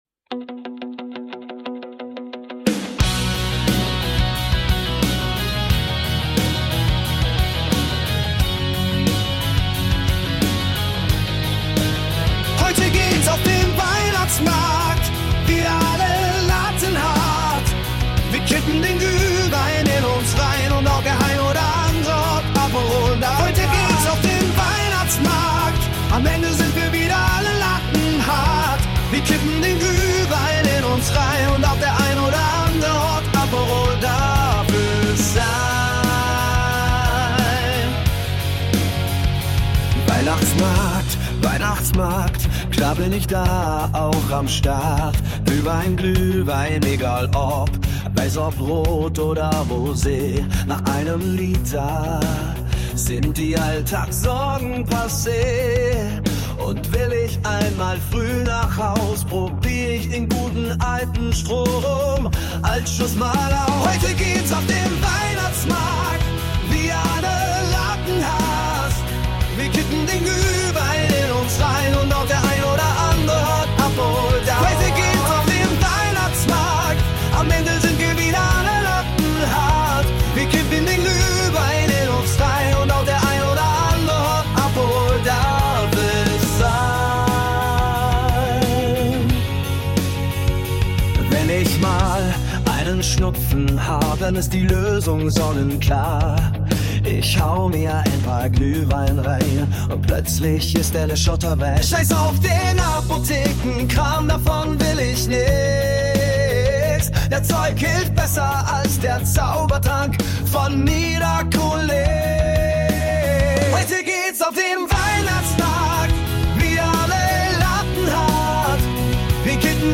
müsste das leider eine KI übernehmen